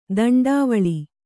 ♪ daṇḍāvaḷi